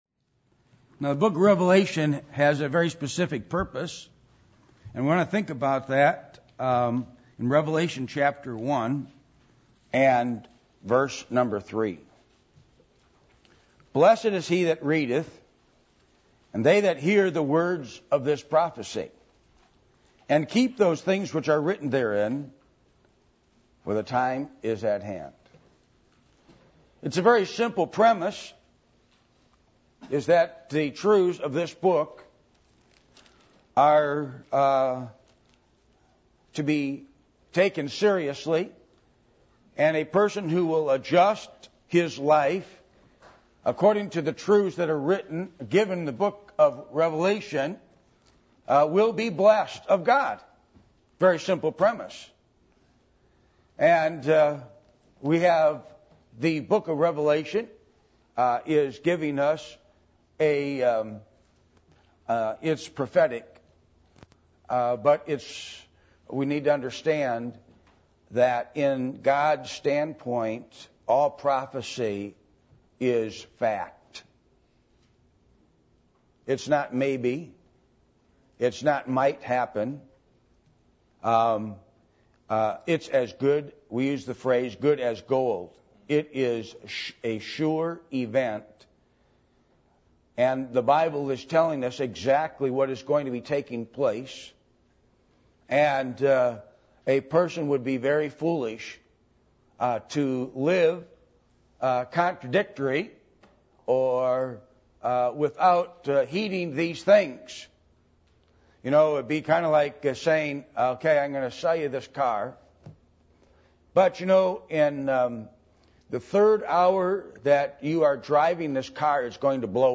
Passage: Revelation 4:1-11 Service Type: Sunday Morning %todo_render% « How To Interpret The Bible